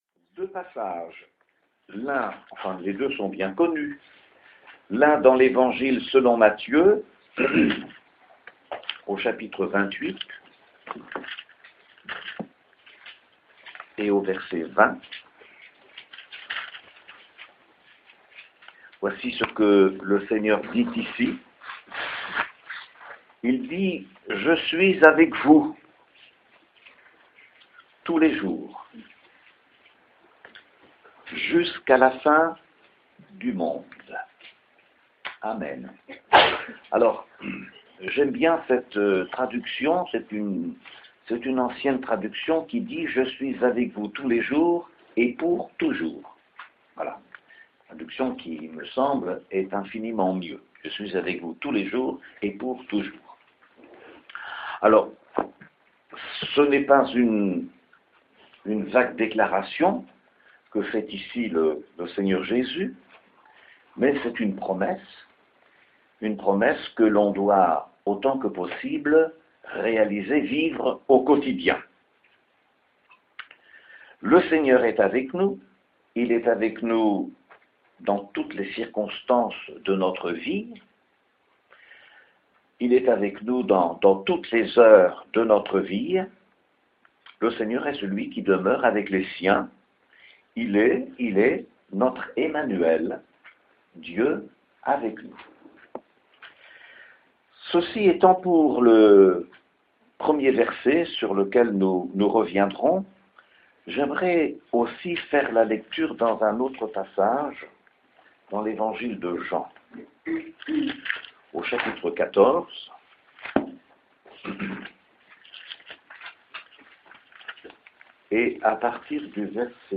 Message audio